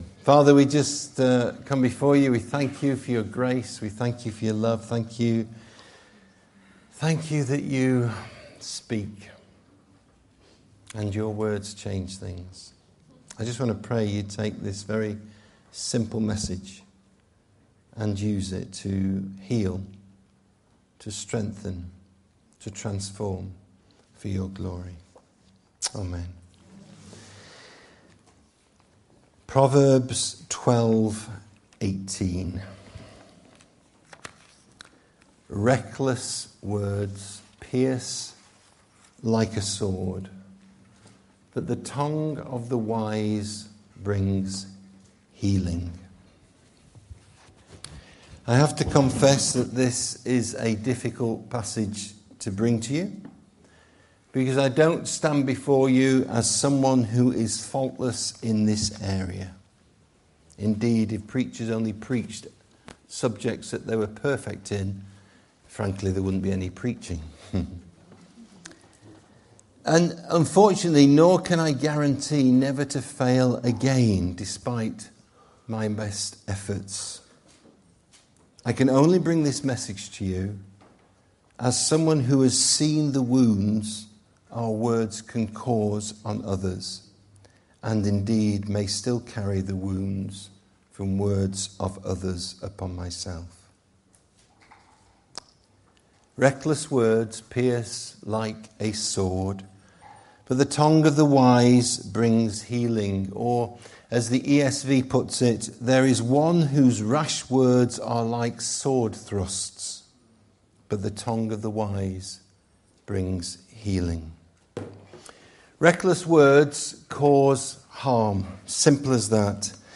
This sermon reflects on the lasting impact our words can have, and encourages us to choose words of wisdom that bring healing to others.